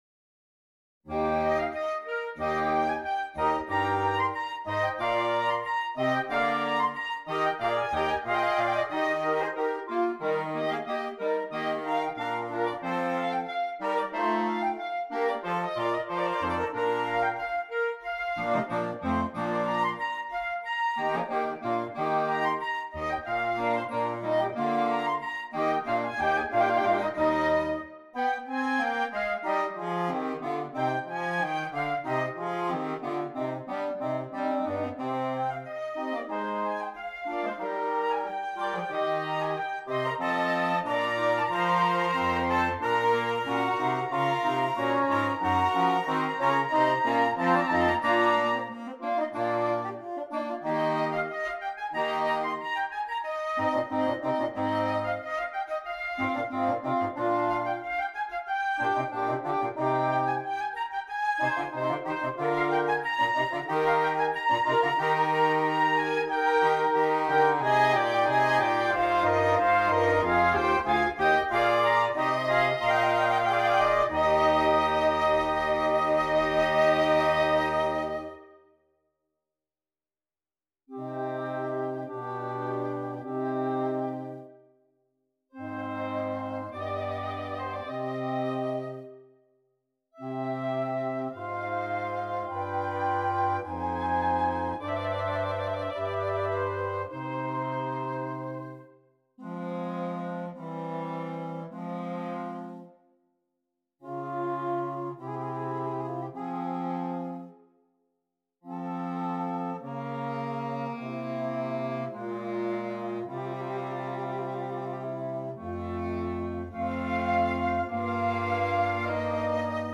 Interchangeable Woodwind Ensemble
Difficulty: Medium-Difficult Order Code